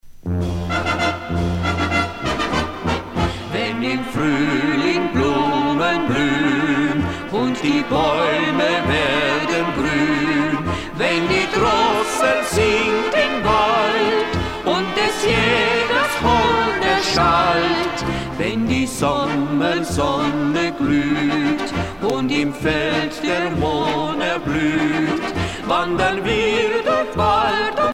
danse : valse
Pièce musicale éditée